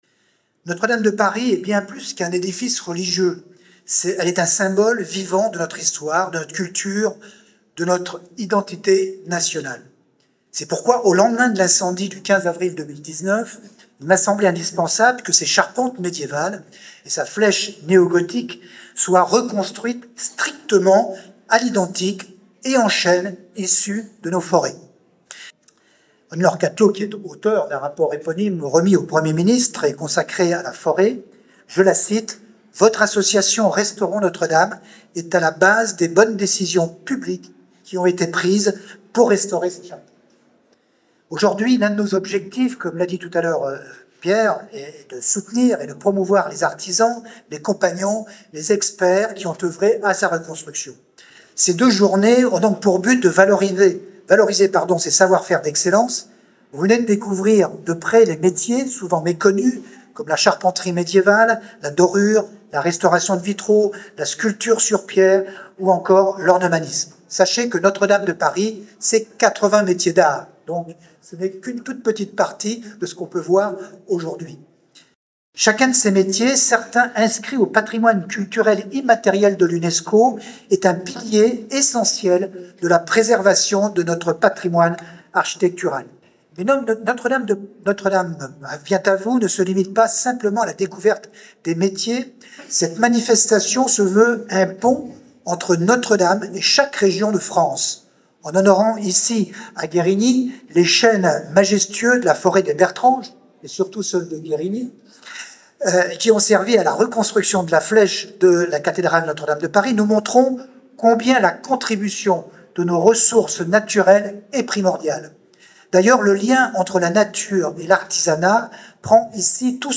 Intégralité de l'intervention